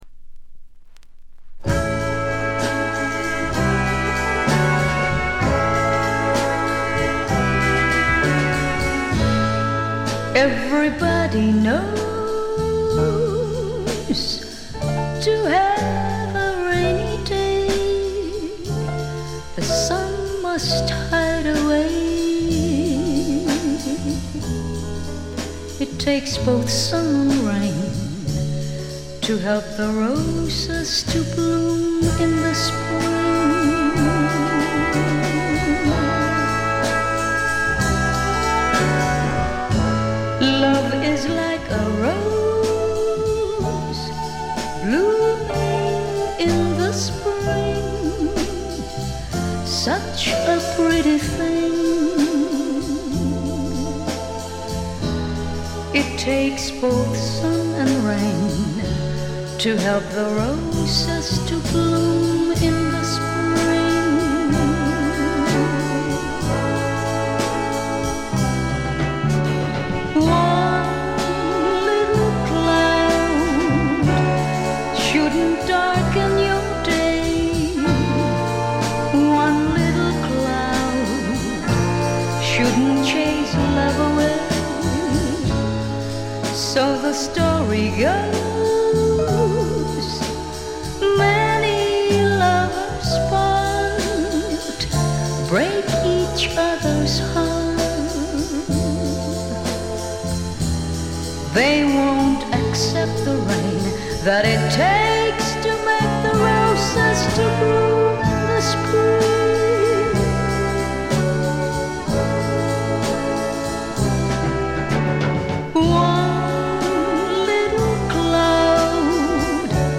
フィリピン出身の女性シンガー
ライブ録音らしくバンドと一体感のあるグルーヴが素晴らしいです。
使用感という意味では新品同様極美品ですが、自主盤らしいプレス起因と思われる軽微なチリプチが少し聴かれます。
試聴曲は現品からの取り込み音源です。